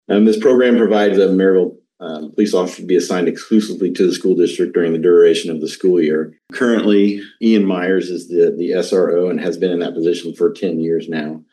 Maryville Police Chief Mike Stolte outlined the SRO program at City Council on Tuesday.